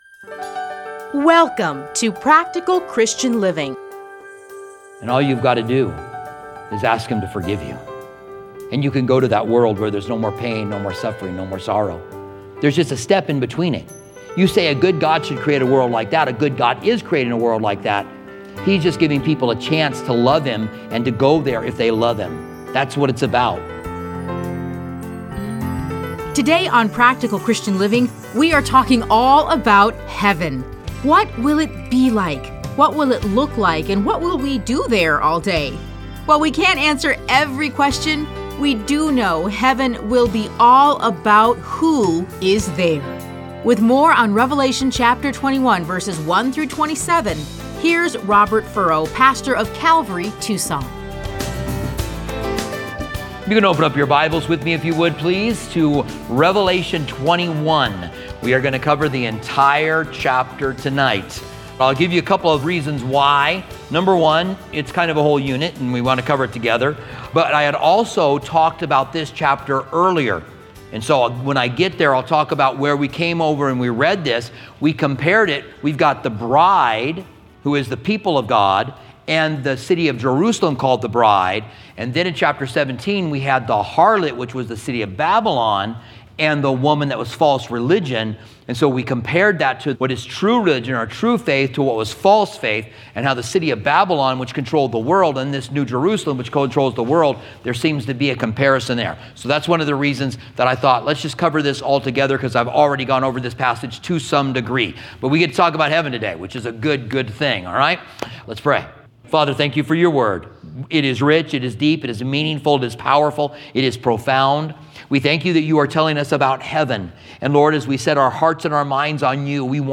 Listen to a teaching from Revelation 21:1-27.